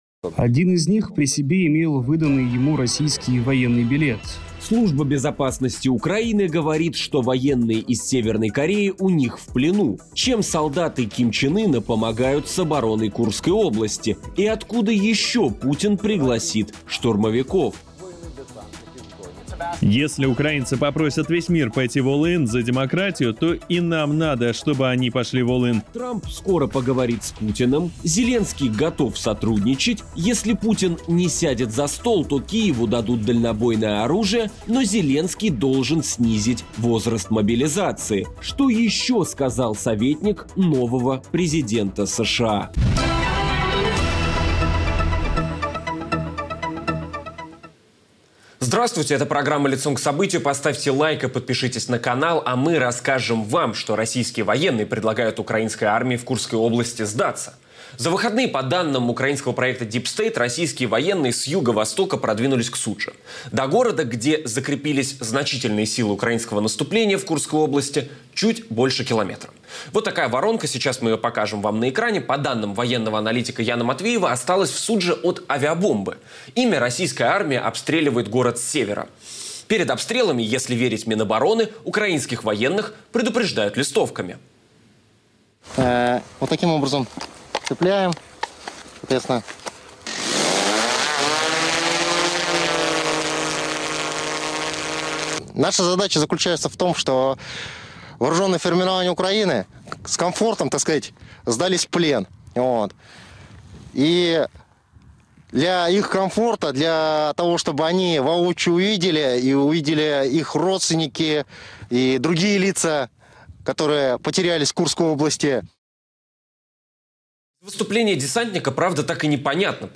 В ежедневном режиме анализируем главные события дня. Все детали в прямом эфире, всегда Лицом к Событию